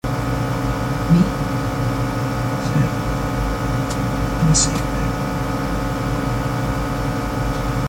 2. The DVD drive sounding like a freakin' Jet Engine.
JetEngine.mp3